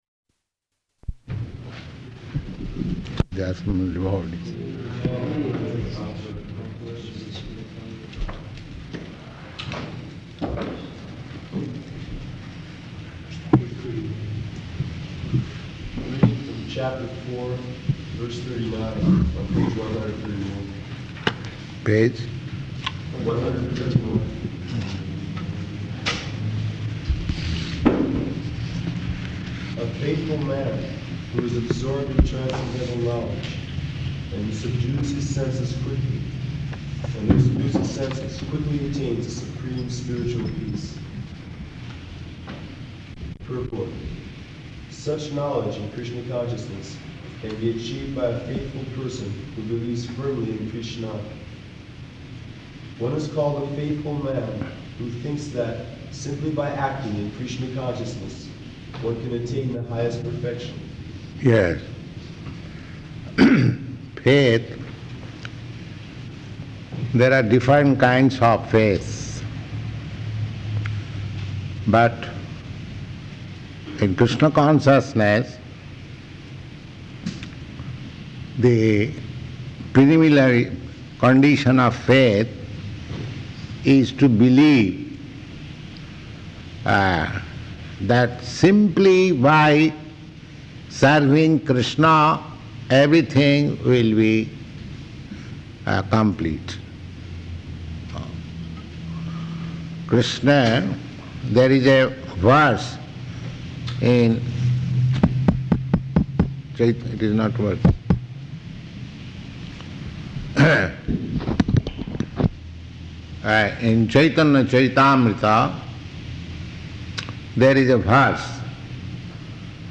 Bhagavad-gītā 4.39–42 --:-- --:-- Type: Bhagavad-gita Dated: January 14th 1969 Location: Los Angeles Audio file: 690114BG-LOS_ANGELES.mp3 Prabhupāda: [aside:] ....the assembled devotees.